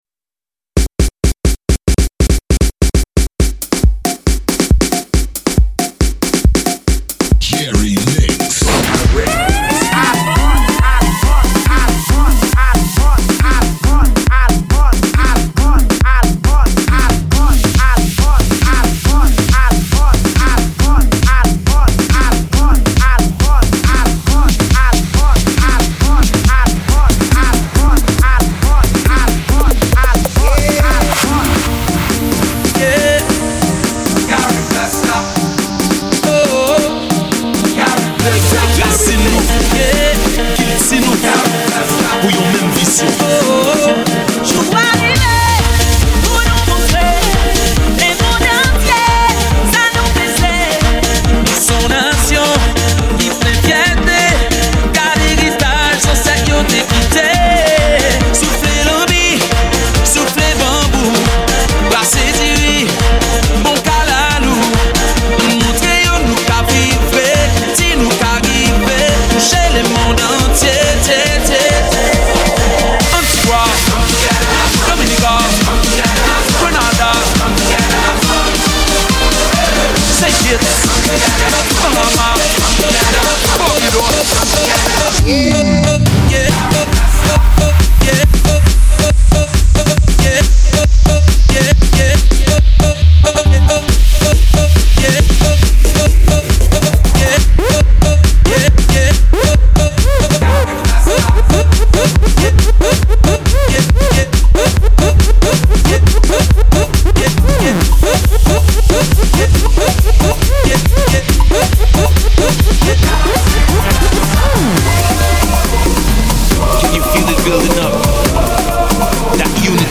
Genre: Mixtape.